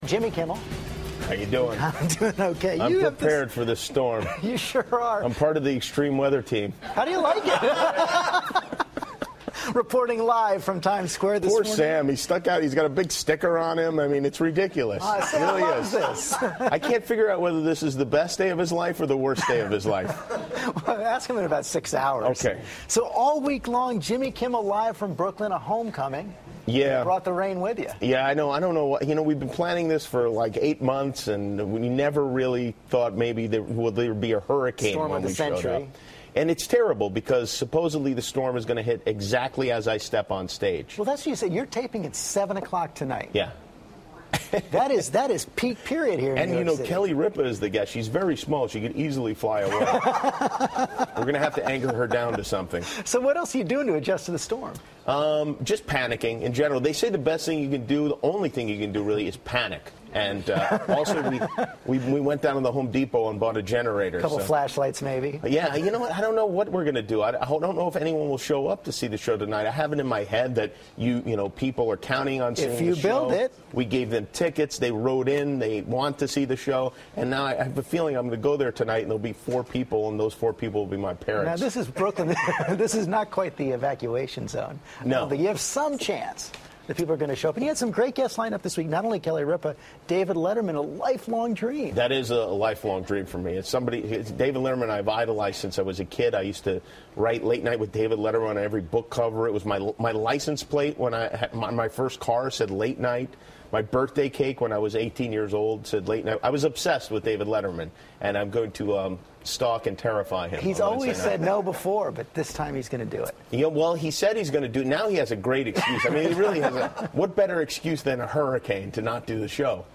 访谈录 2012-10-31&11-02 ABC电视台脱口秀主持吉米·科莫尔访谈 听力文件下载—在线英语听力室